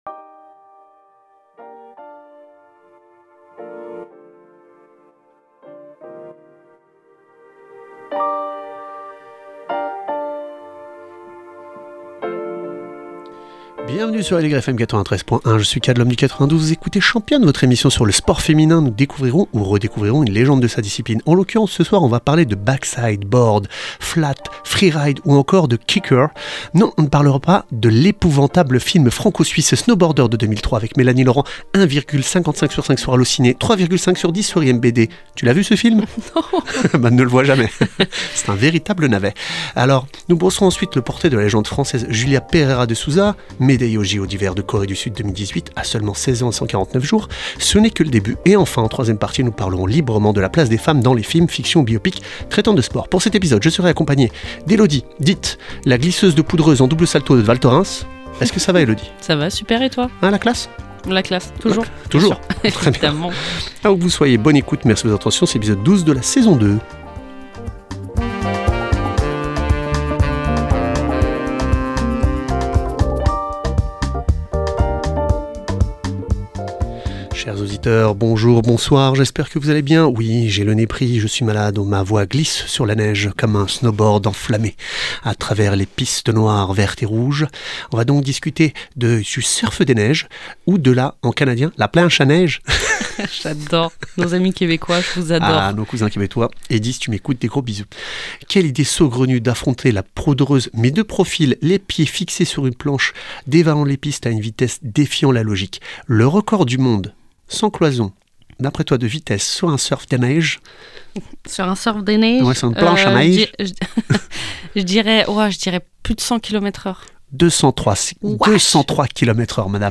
Qu’elles soient amatrices, semi-pros ou pros, nous échangeons avec des invitées inspirantes, qui partagent leurs visions et leurs expériences.